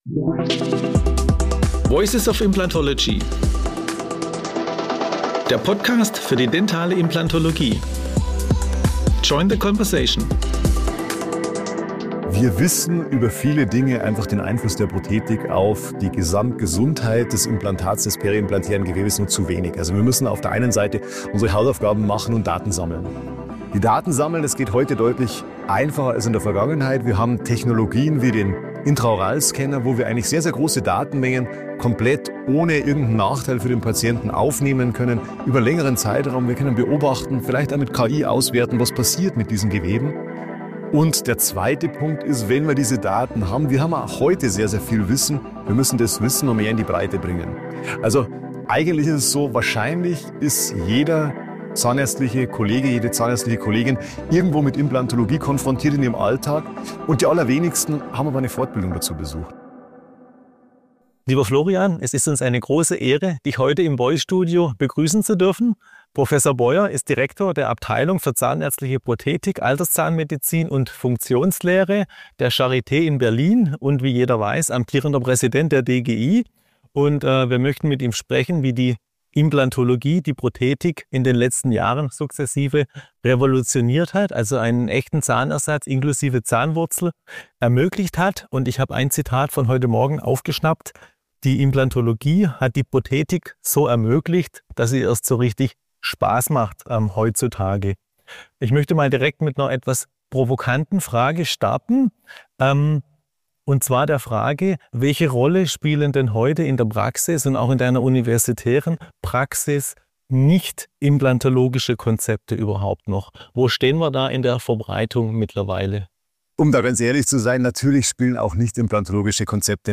Weitere Themen waren die Rolle der Zahnmedizin in der Medizin und prothetische Konzepte im Alter – was ist das bessere Konzept: festsitzend oder herausnehmbar? Das Interview wurde anlässlich des Jubiläumskongresses 25 Jahre Camlog im September 2024 in Metzingen geführt.